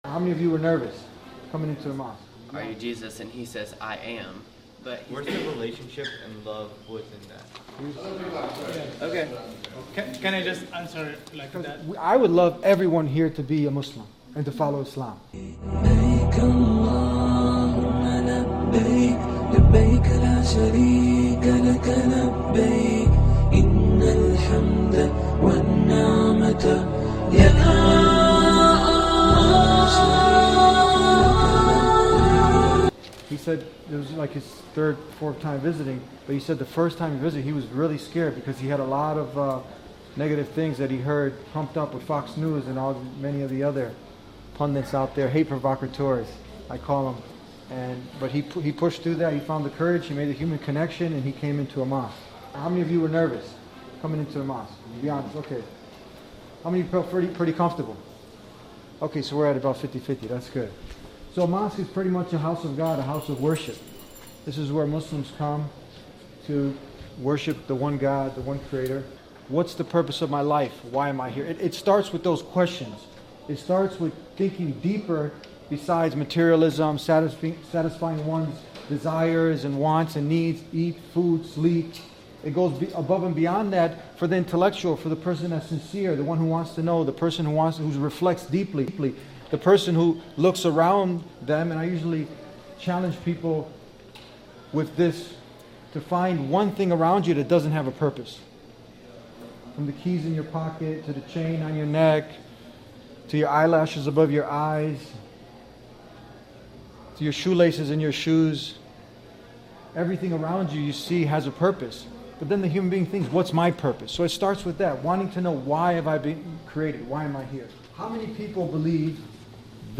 Christian Students ask questions about Islam – The Deen Show